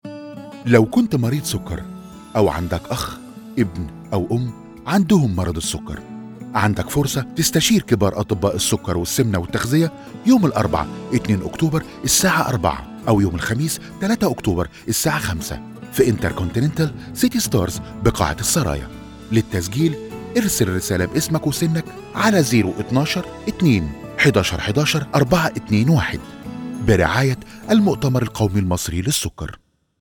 Radio Commercials